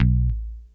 B1 4 F.BASS.wav